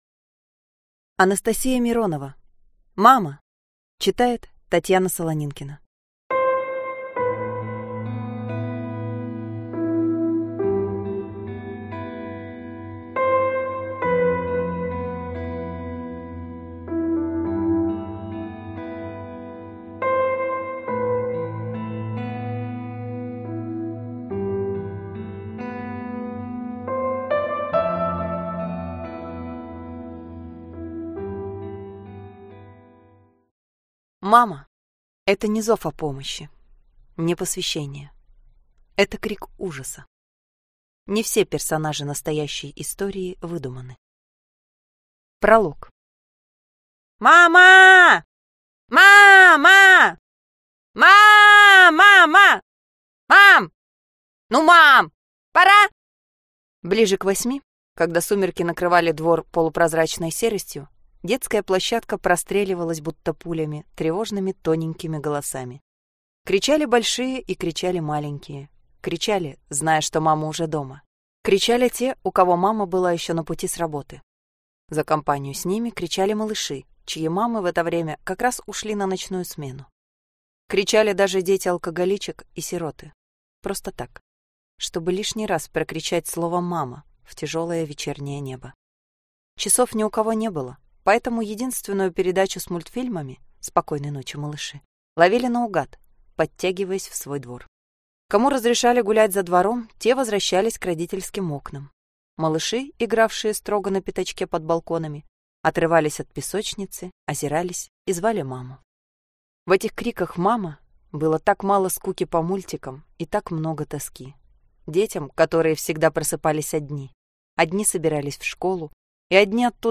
Аудиокнига Мама!!!